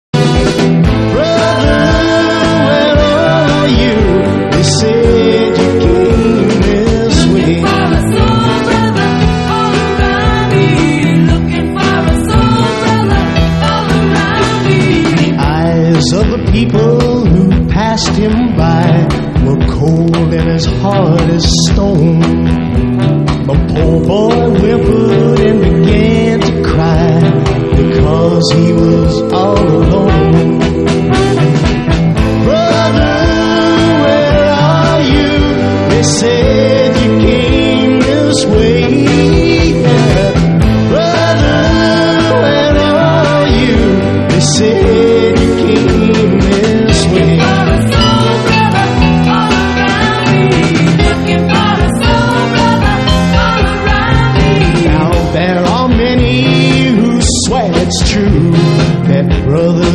ROCK / 60'S / OLDIES (US)